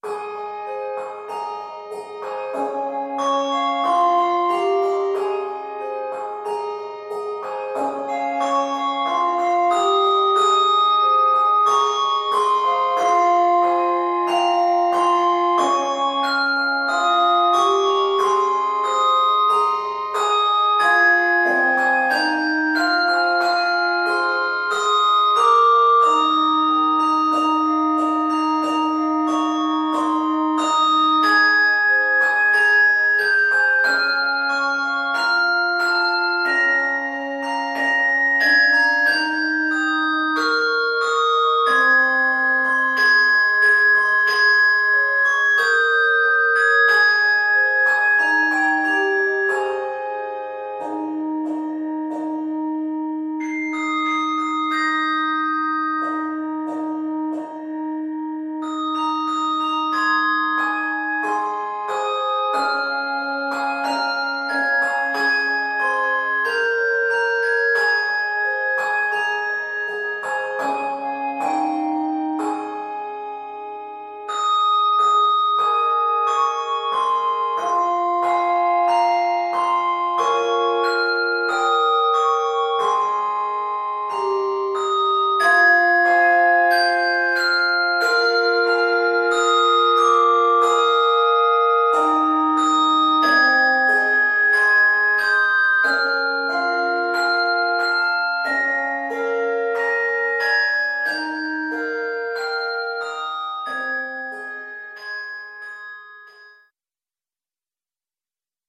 This rendition is set in Ab Major.